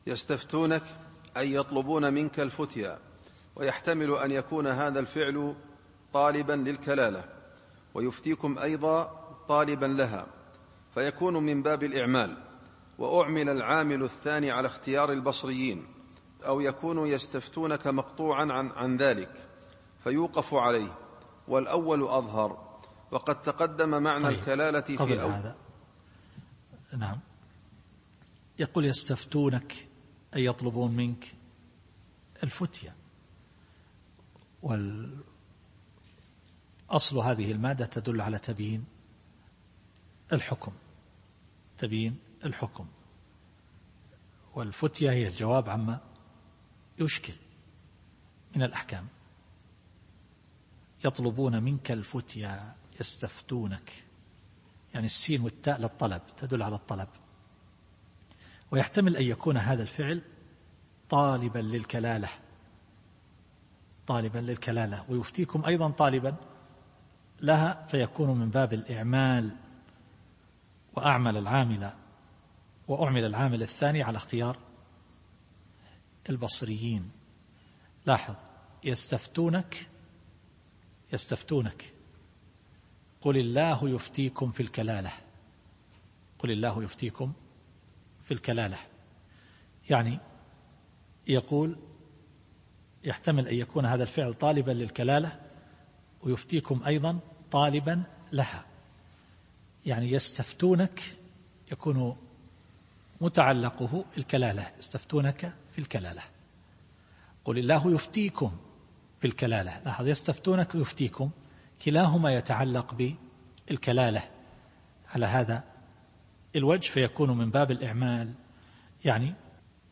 التفسير الصوتي [النساء / 176]